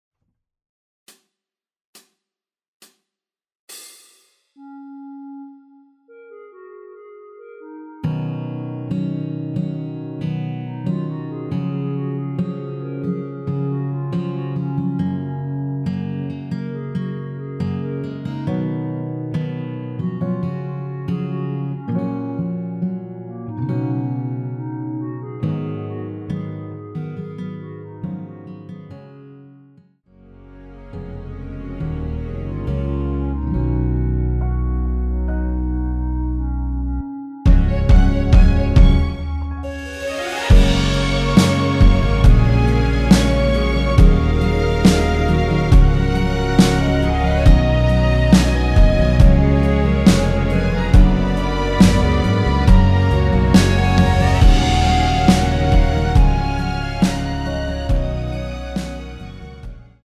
전주 없이 시작하는 곡이라서 시작 카운트 만들어놓았습니다.(미리듣기 확인)
원키에서(-2)내린 (1절앞+후렴)으로 진행되는 멜로디 포함된 MR입니다.
Gb
앞부분30초, 뒷부분30초씩 편집해서 올려 드리고 있습니다.